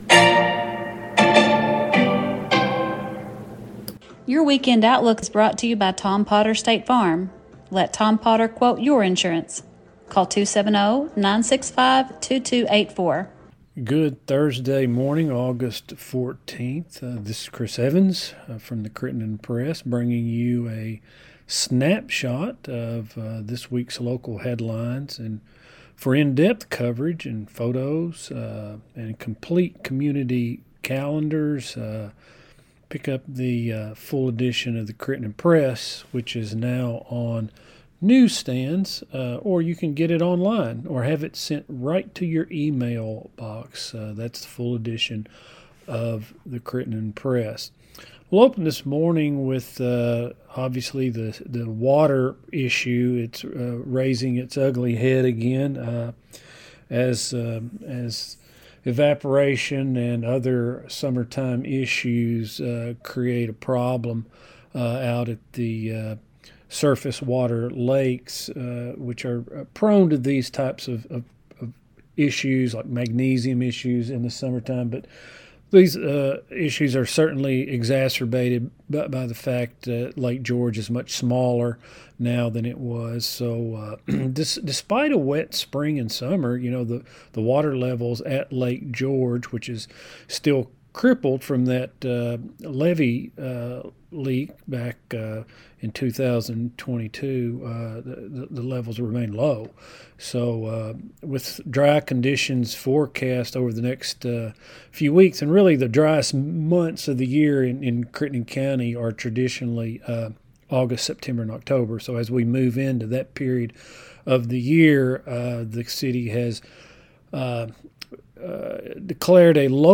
STATE FARM | Thursday NEWScast